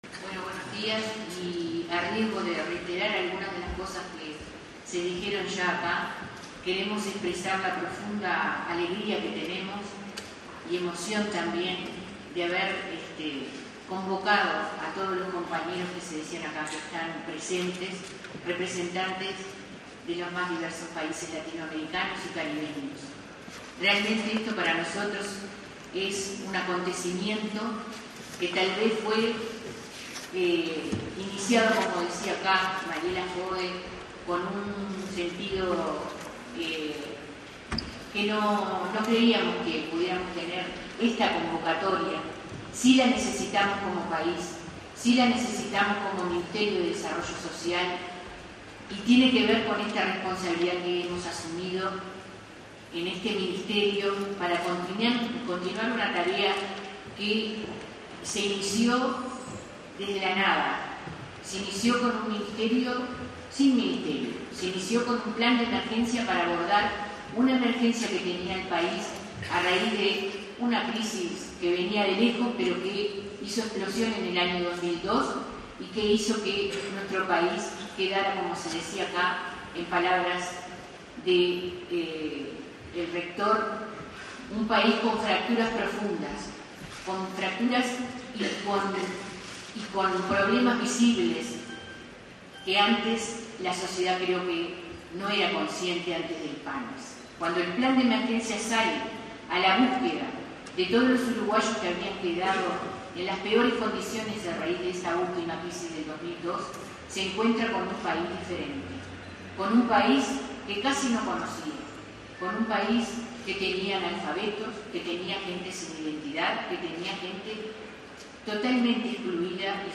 Palabras de la Ministra de Desarrollo Social, Ana  Vignoli, durante el encuentro “Economía social y solidaria”, realizado en el Paraninfo de la Universidad de la República.